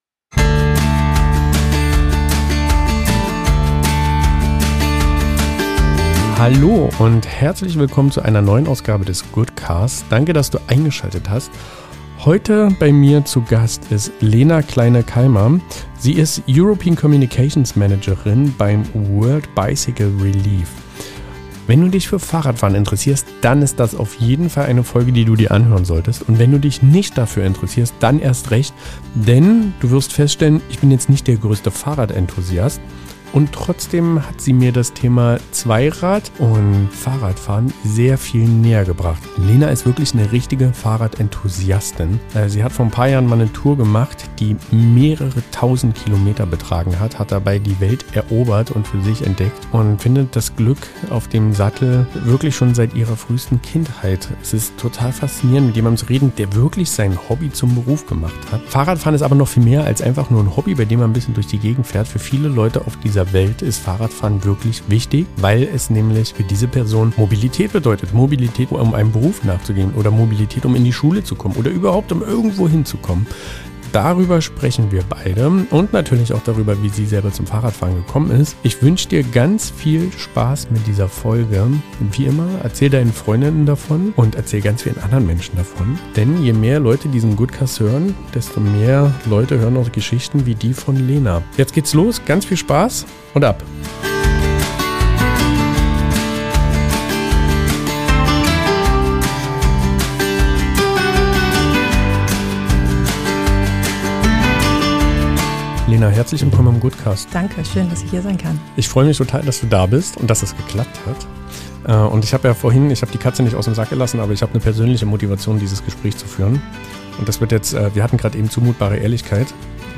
Ein Gespräch, das berührt, herausfordert und zeigt, wie ein einfaches Fahrrad unsere Welt verändern kann.